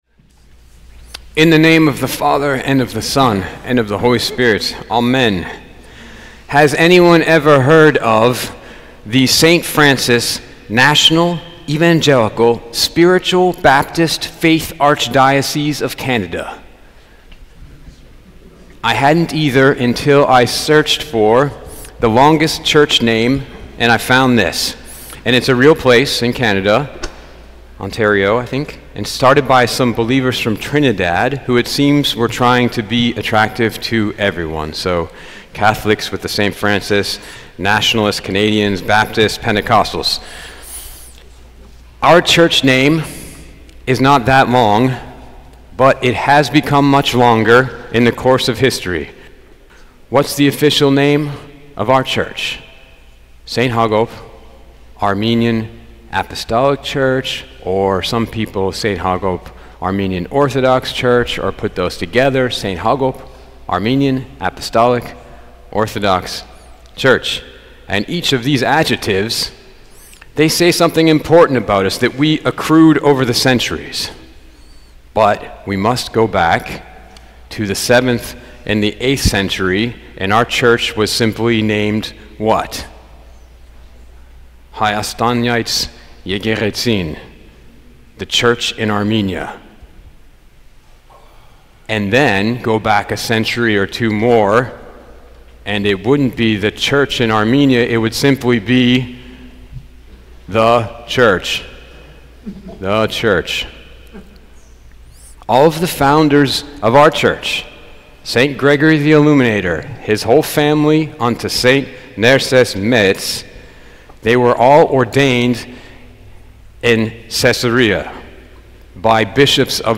Podcasts Sermons St. Hagop Armenian Church